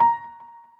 piano_last38.ogg